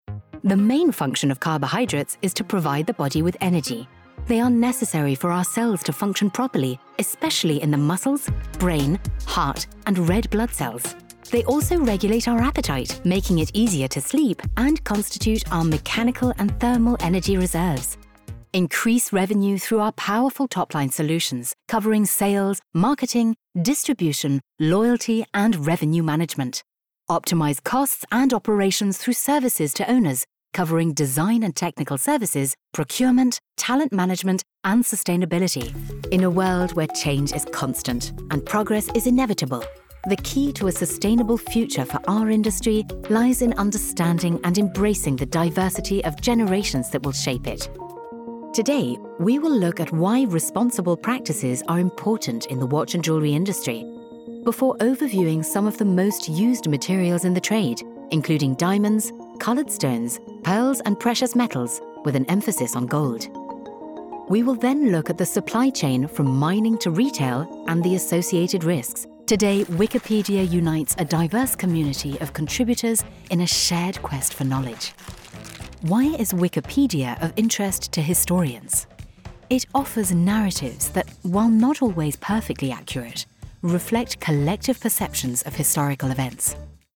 Engels (Brits)
Warm, Opvallend, Veelzijdig, Vertrouwd, Natuurlijk
E-learning